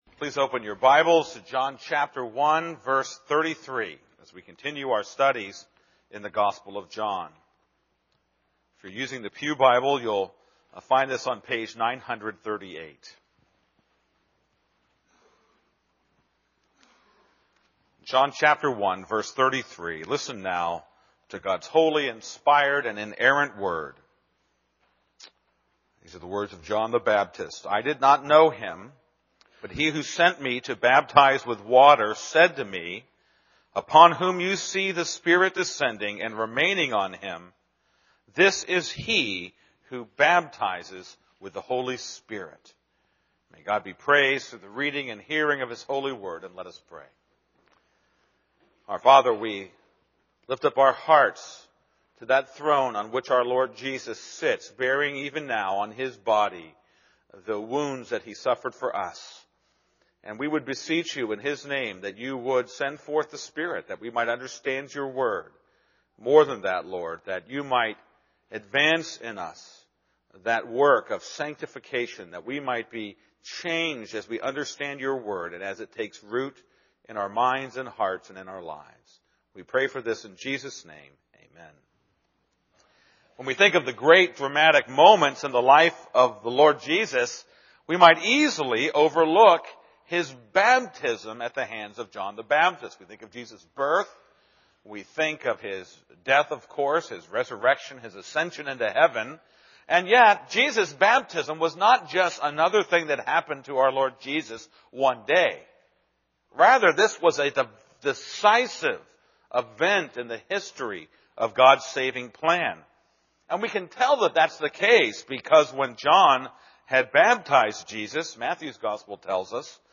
This is a sermon on John 1:33.